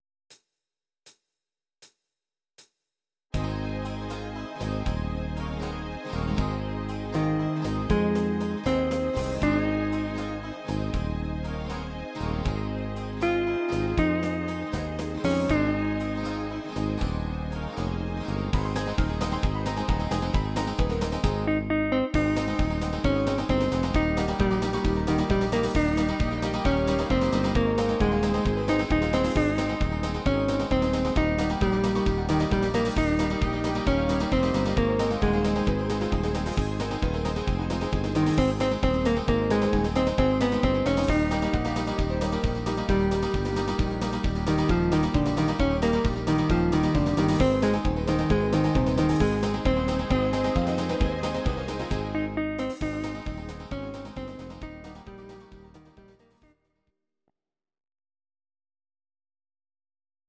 Audio Recordings based on Midi-files
Oldies, German, 1960s